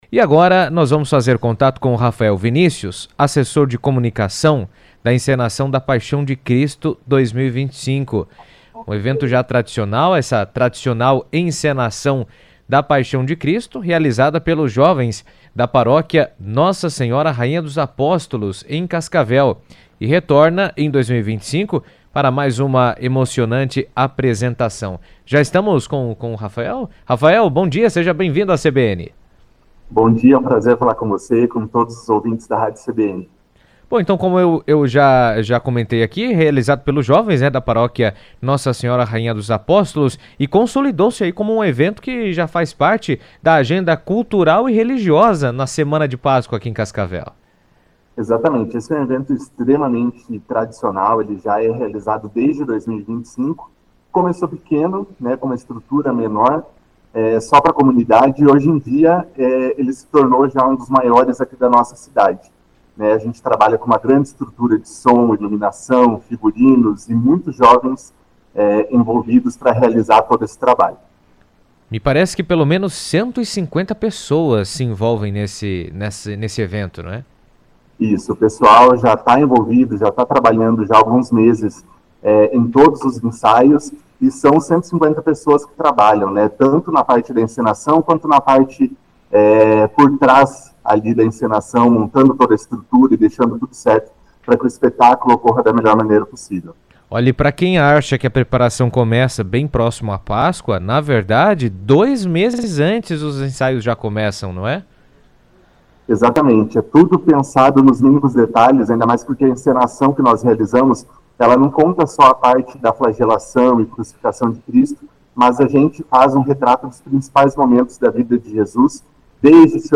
trouxe detalhes do evento durante entrevista na CBN.